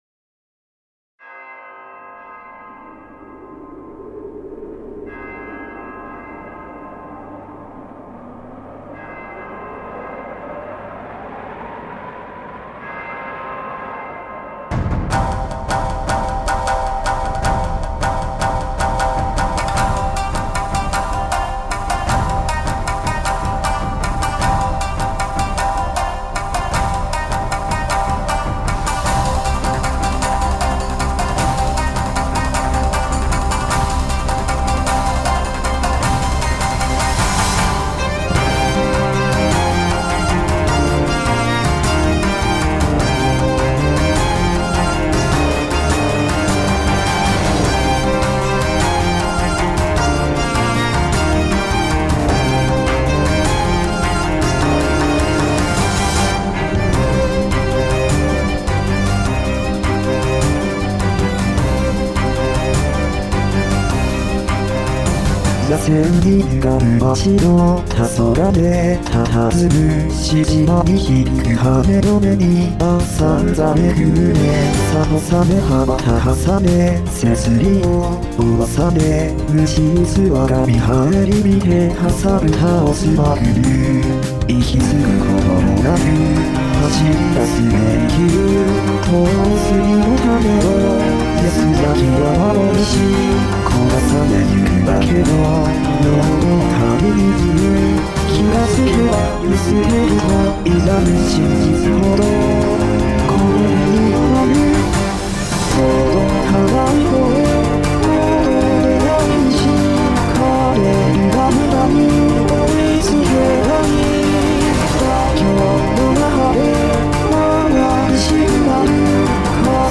やっぱり男らしさ重視でに切り替えました・・・・
サビが↑音源であとはだいたい平音源で一番低そうな所は↓音源です。